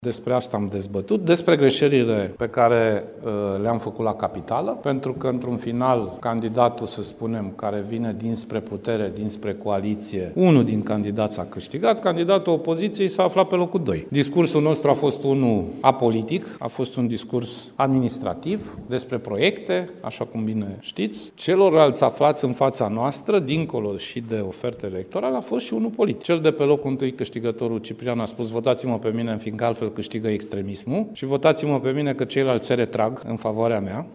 Președintele PSD, Sorin Grindeanu: „Discursul nostru a fost apolitic, a fost administrativ, despre proiecte”